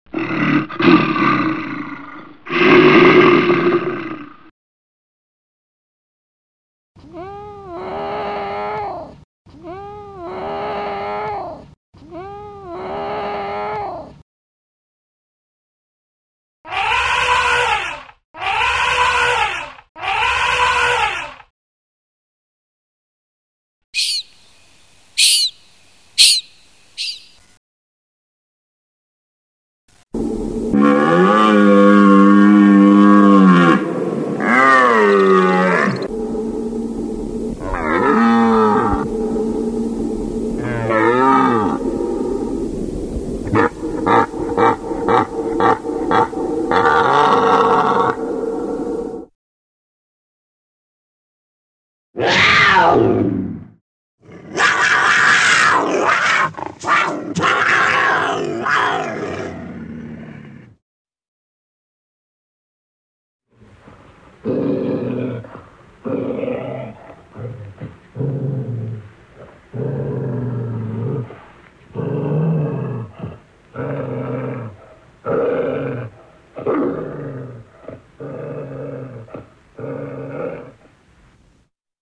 Фонотека «Голоса животных»